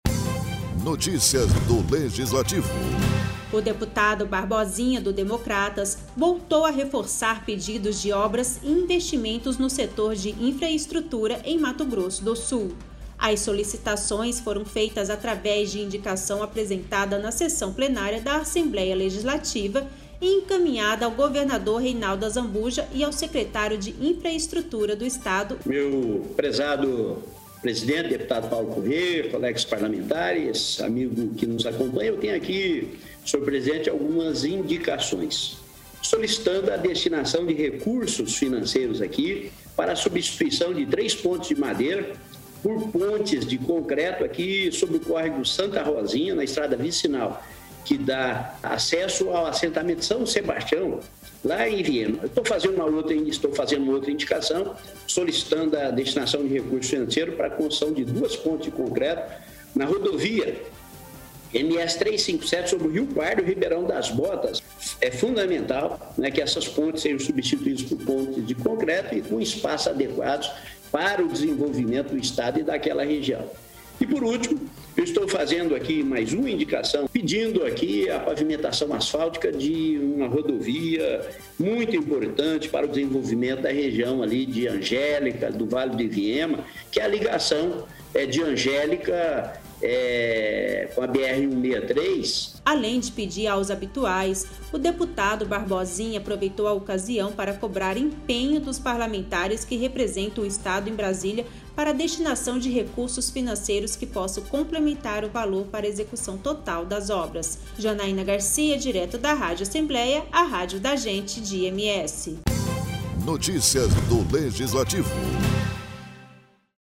O deputado Barbosinha (Democratas), fez uso da palavra na sessão plenária desta terça-feira (15) para reforçar pedidos de obras e investimentos no setor de infraestrutura do Estado.